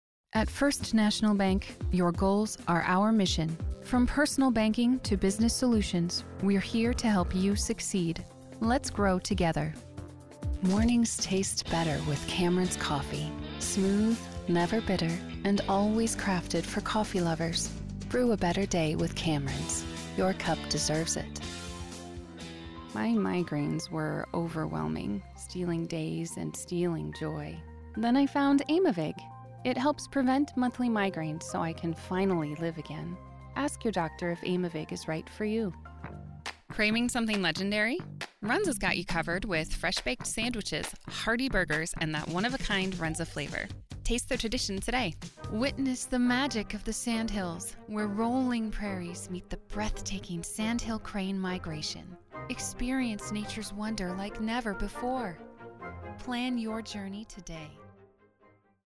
Authentic, Professional voiceovers for brands, videos, and stories that deserve to be heard.
Commercial Demo